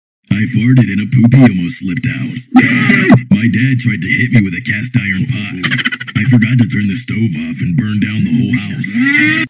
Fart